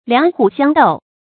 注音：ㄌㄧㄤˇ ㄏㄨˇ ㄒㄧㄤ ㄉㄡˋ
兩虎相斗的讀法